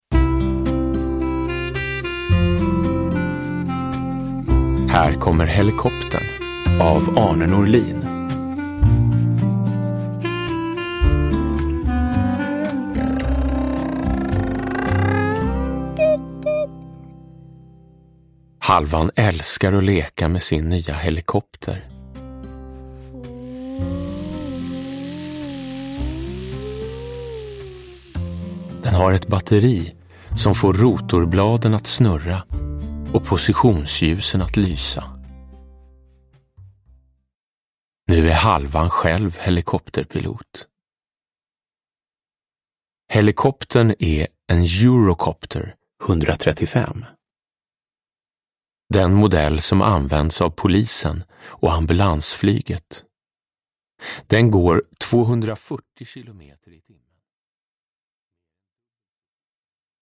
Nedladdningsbar ljudbok
Uppläsare: Jonas Karlsson